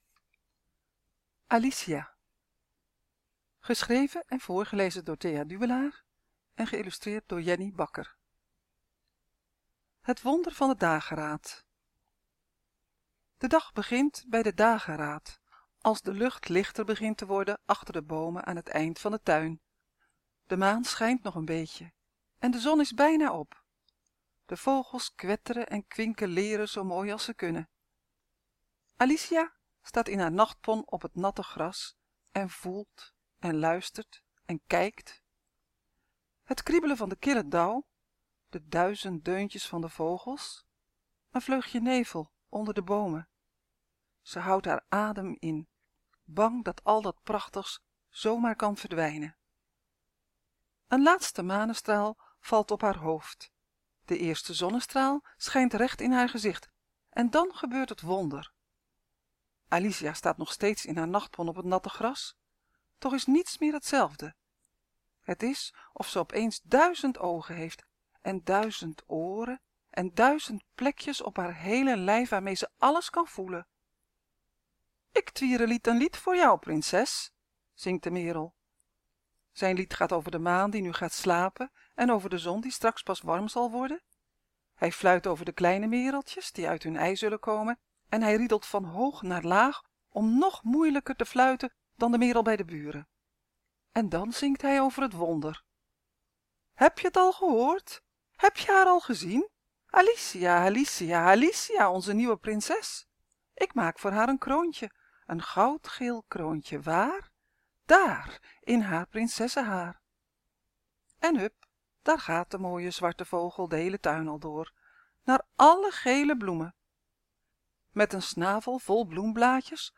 kort verhaal